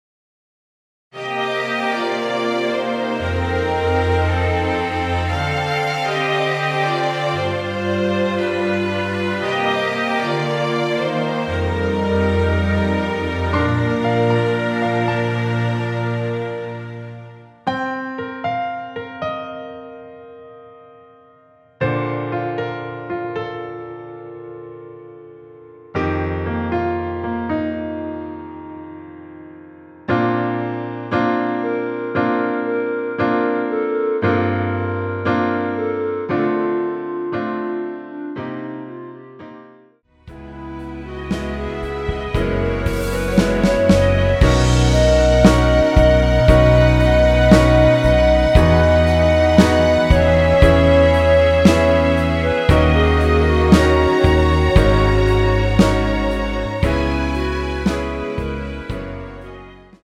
내린 멜로디 MR입니다.
2절 부분은 삭제하고 어둠이 찾아 들어로 연결 됩니다.
Bb
앞부분30초, 뒷부분30초씩 편집해서 올려 드리고 있습니다.
중간에 음이 끈어지고 다시 나오는 이유는